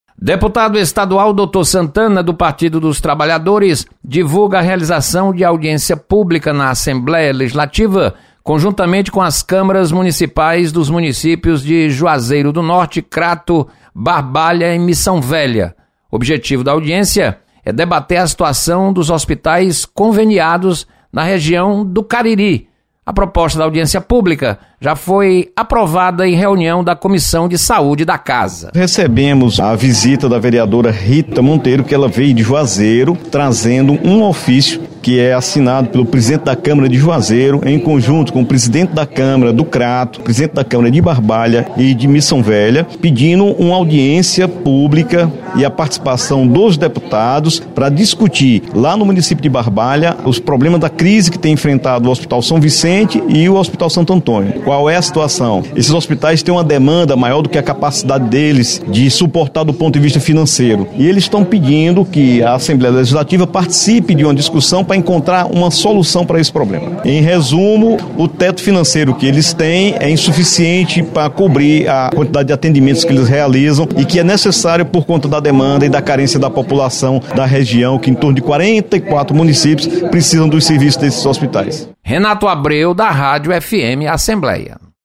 Deputado Dr. Santana anuncia audiência para debater sobre hospital de Barbalha. Repórter